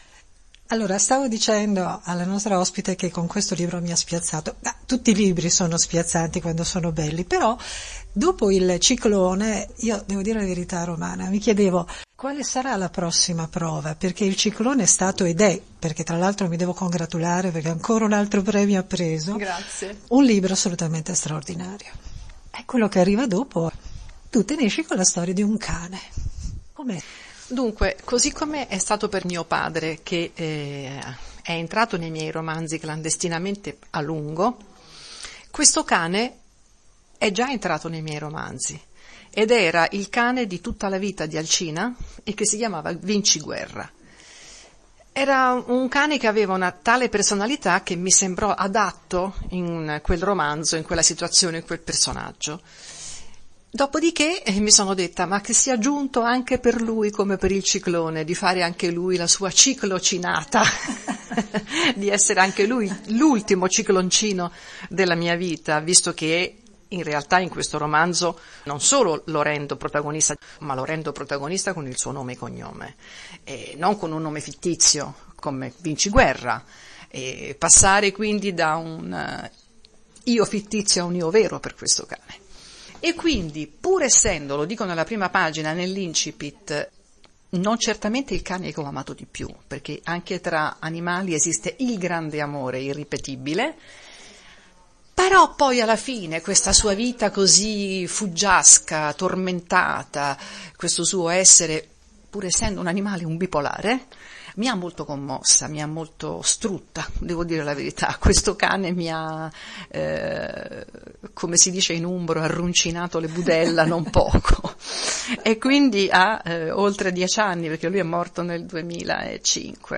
“Il mio cane del Klondike”: chiacchierata con Romana Petri